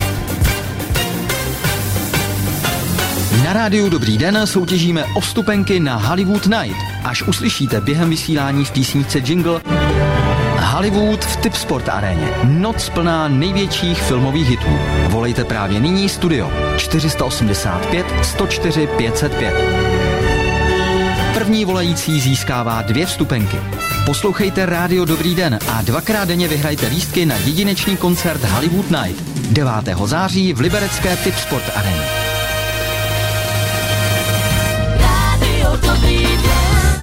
Úkolem je dovolat se jako první na číslo do studia, výherní signál uslyšíte v přiloženém self-promotion.